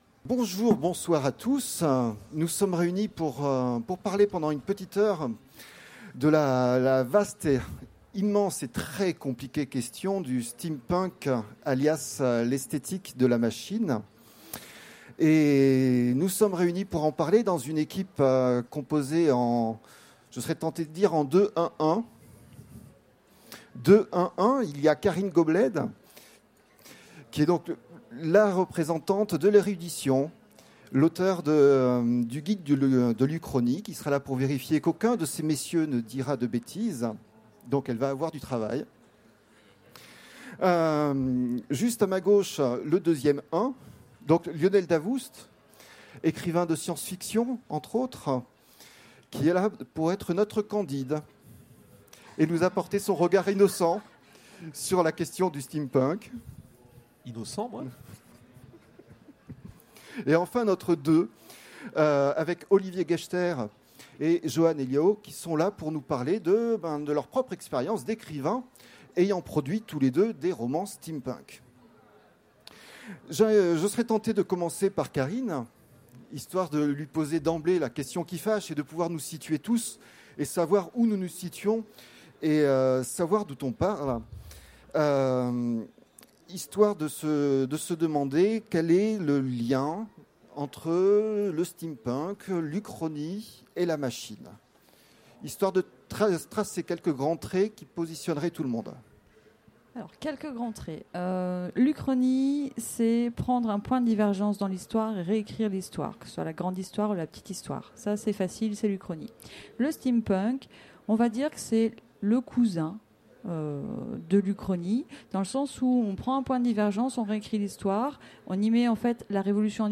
Utopiales 2016 : Conférence Le steampunk, une esthétique de la machine ?